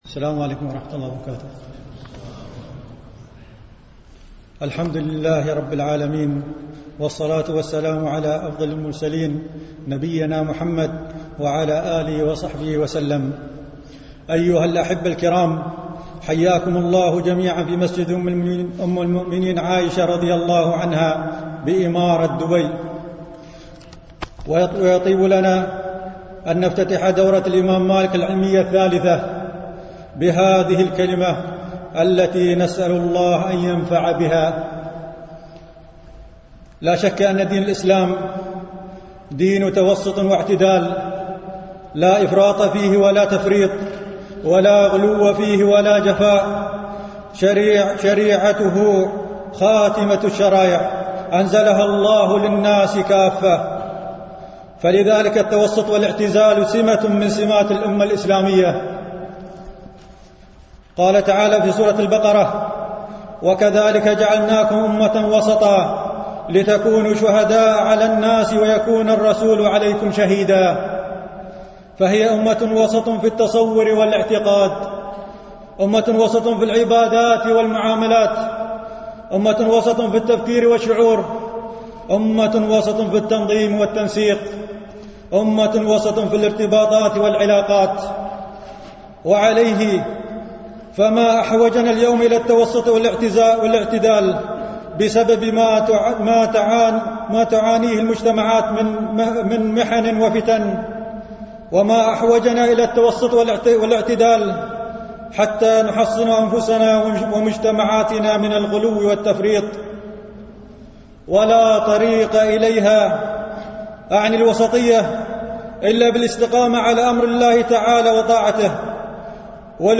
الندوة الافتتاحية: الوسطية والاعتدال منهج شرعي
MP3 Mono 22kHz 32Kbps (CBR)